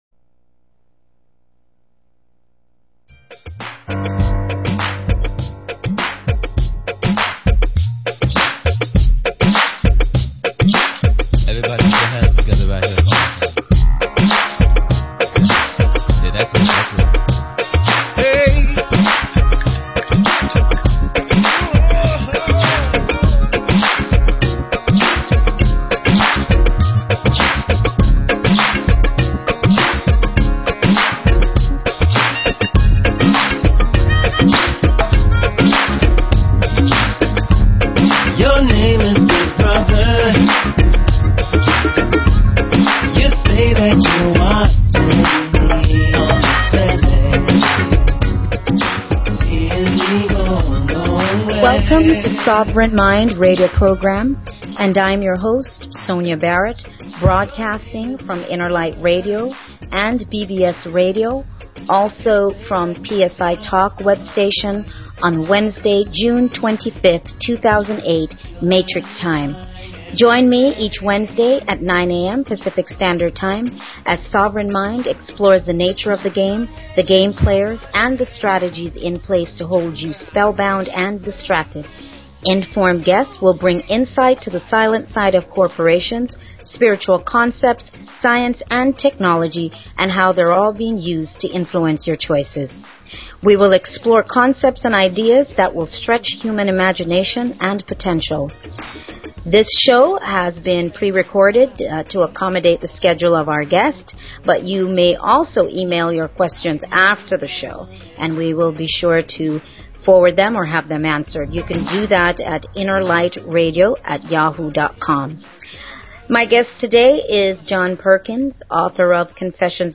Talk Show Episode, Audio Podcast, Sovereign_Mind_Radio and Courtesy of BBS Radio on , show guests , about , categorized as